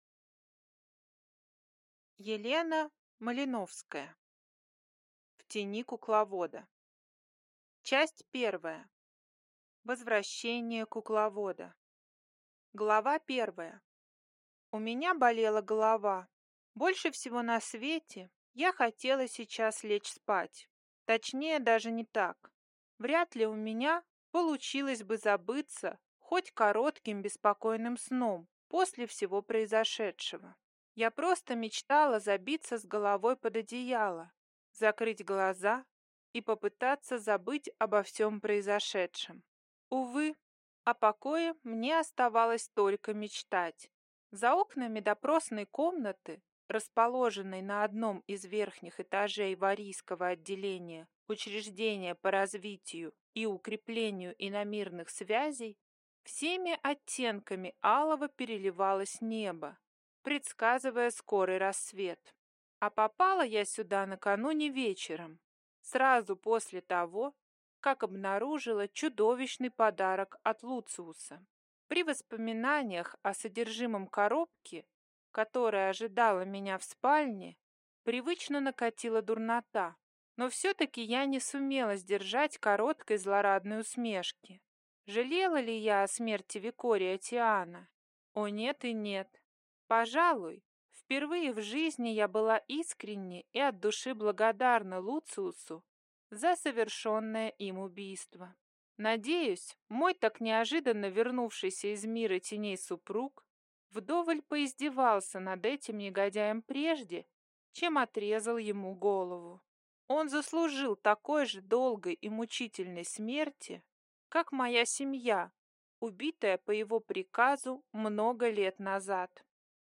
Аудиокнига В тени кукловода | Библиотека аудиокниг